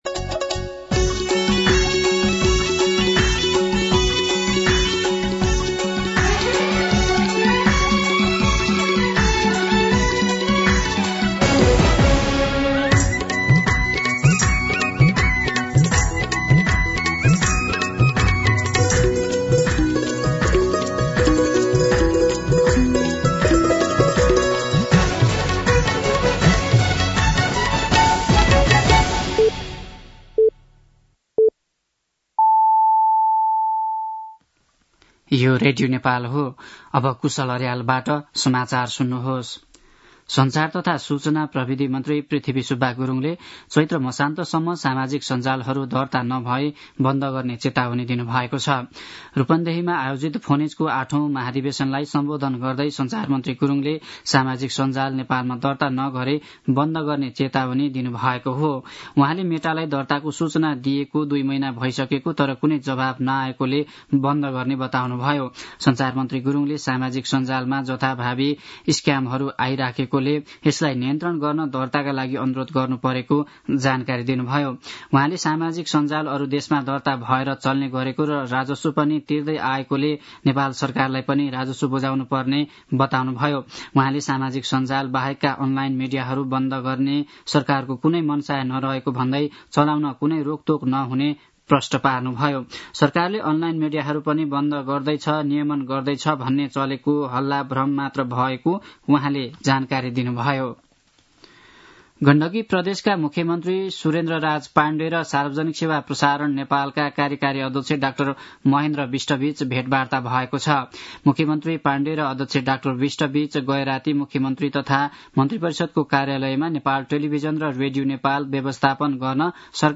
An online outlet of Nepal's national radio broadcaster
दिउँसो १ बजेको नेपाली समाचार : ३० चैत , २०८१
1-pm-Nepali-News-3.mp3